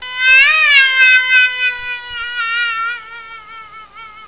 Lätena har en slående likhet med en harunges skrik.
Här kan du provlyssna lockpipans musläte»